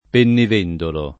pronunzia dell’ -e- tonica come in vendere